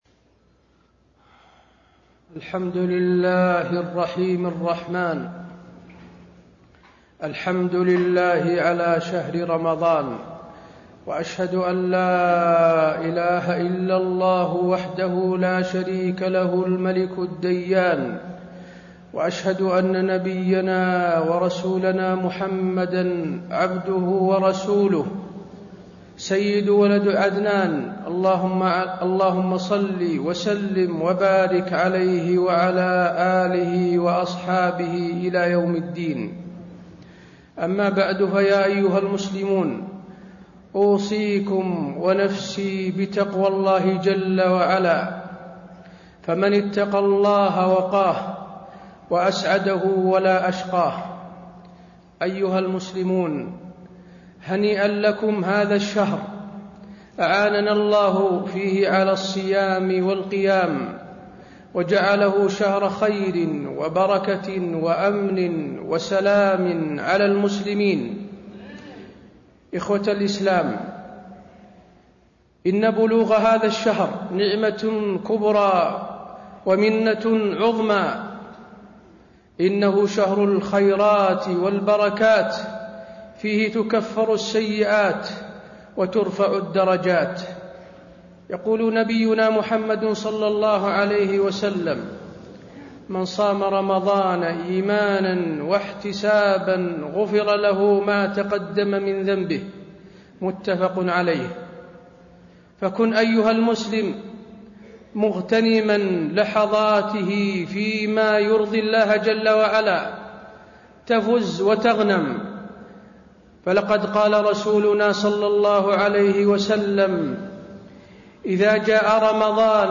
تاريخ النشر ١ رمضان ١٤٣٣ هـ المكان: المسجد النبوي الشيخ: فضيلة الشيخ د. حسين بن عبدالعزيز آل الشيخ فضيلة الشيخ د. حسين بن عبدالعزيز آل الشيخ أظلكم شهر المغفرة والرحمة